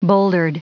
Prononciation du mot bouldered en anglais (fichier audio)
Prononciation du mot : bouldered